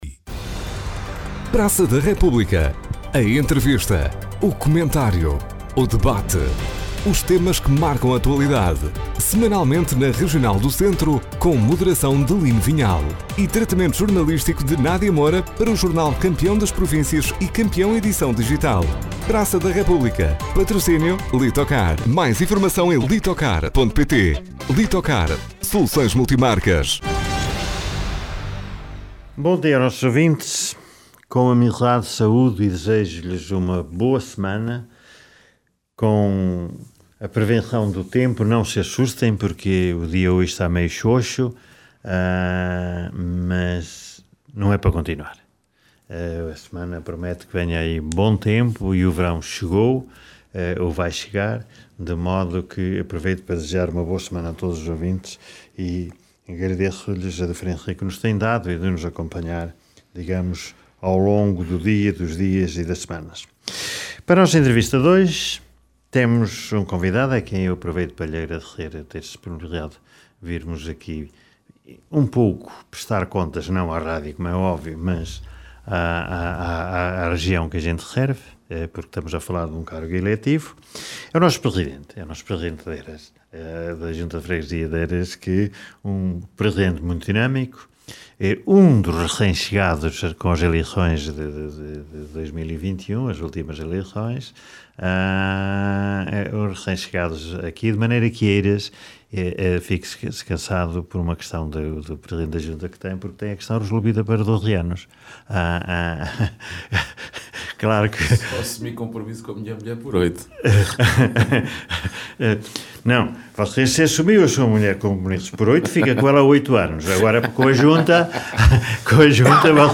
Na edição desta semana do ‘Praça da República’, a entrevista a Luís Correia, presidente da União das Freguesias de Eiras e São Paulo de Frades.